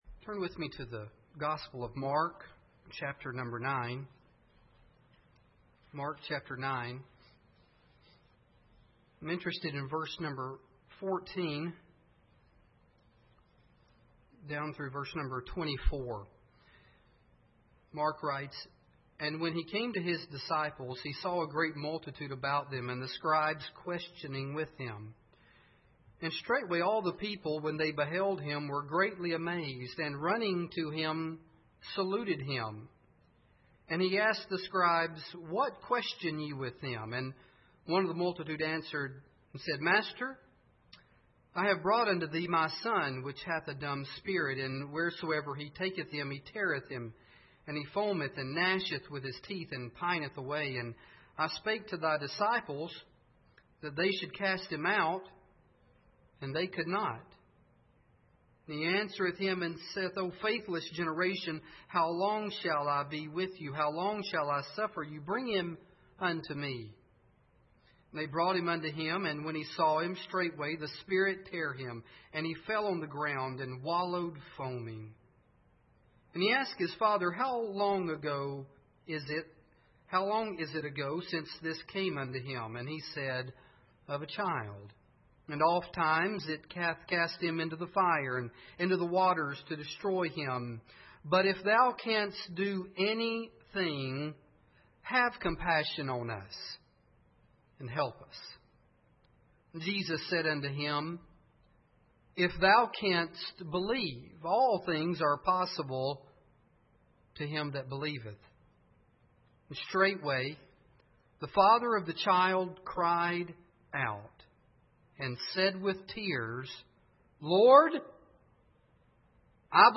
September 13, 2009 Help Thou Mine Unbelief Speaker: Our Brother Series: General Passage: Mark 9:14-24 Service Type: Sunday Evening Bible Text: Mark 9:14-24 | Preacher: Our Brother | Series: General « Saved By Hope Fear »